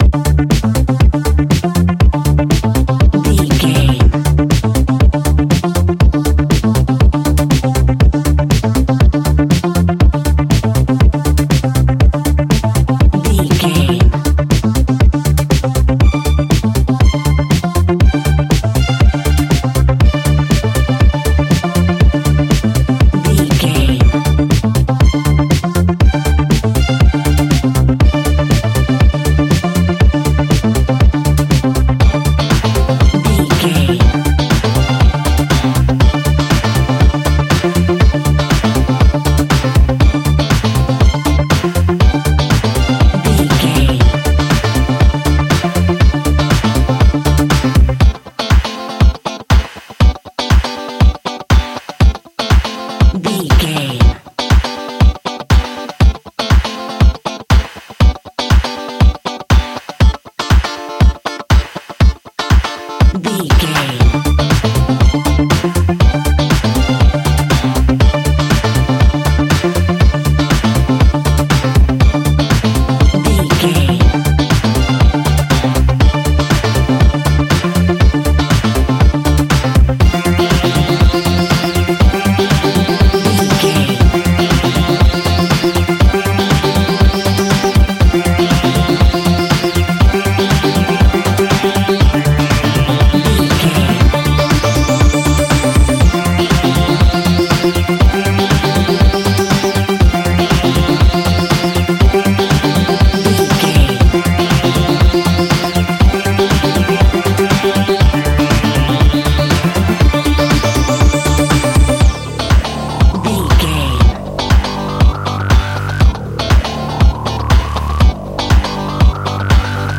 Ionian/Major
D
house
electro dance
synths
techno
trance
instrumentals